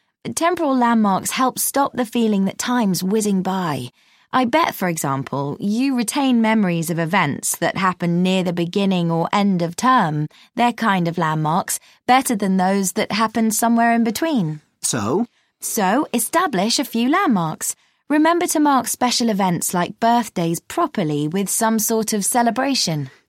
Now have a look at these examples where the /d/ is dropped because of elision and then the /n/ is assimilated into /m/.
Notice how the speaker pronounces /ˈlæmmɑːk/ (Cambridge Advanced test).
landmark-as-lammark-advanced.mp3